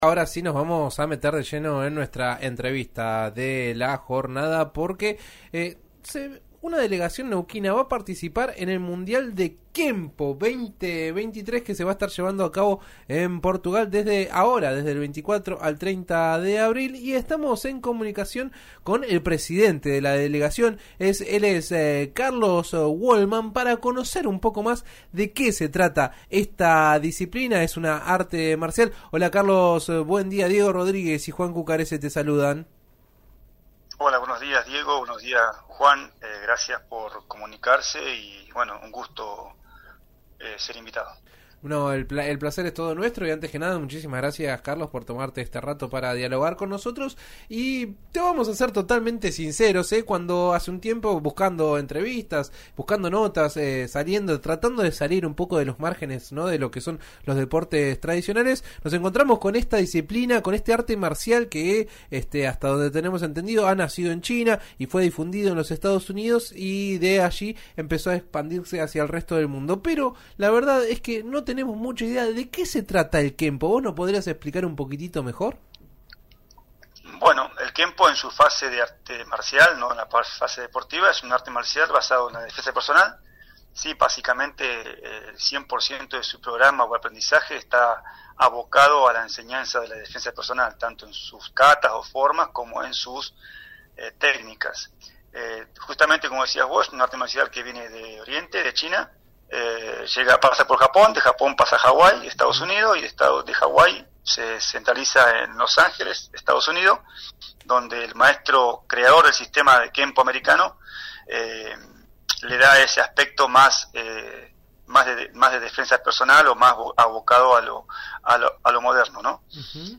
en dialogo con el programa «Entre Redes» de RÍO NEGRO RADIO.